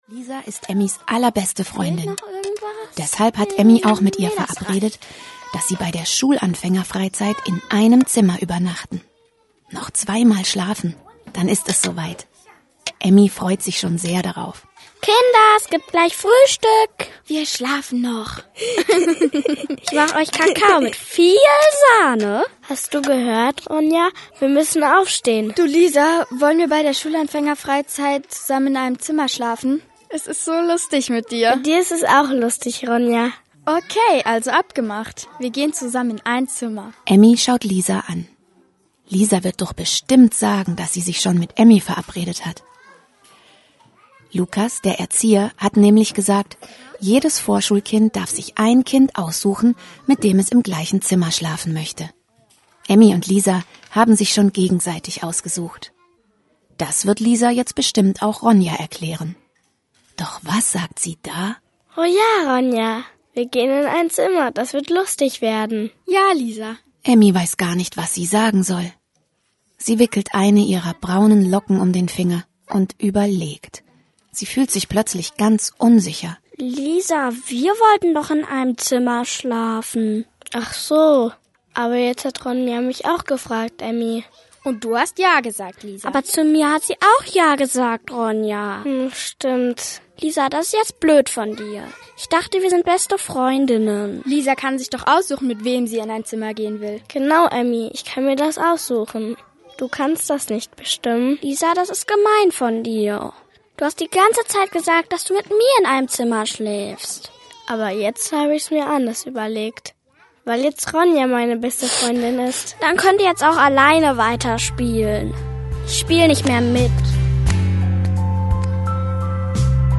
Hörspiele